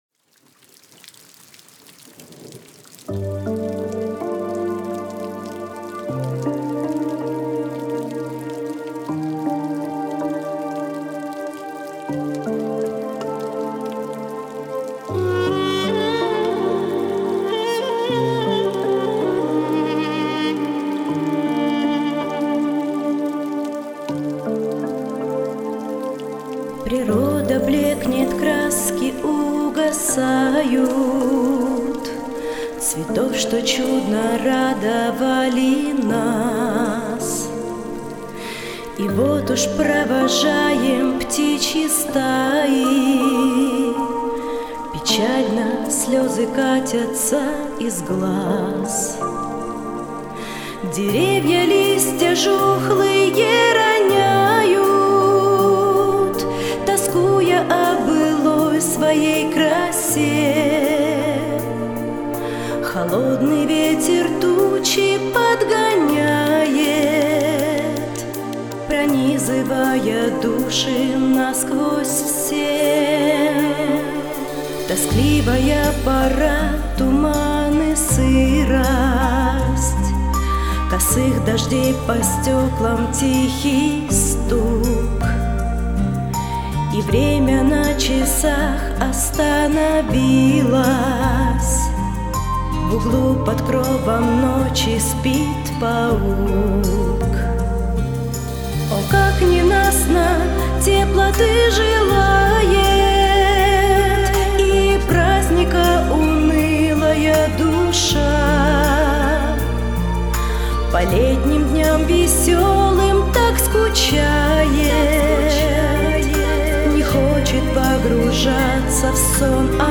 песня
156 просмотров 266 прослушиваний 11 скачиваний BPM: 84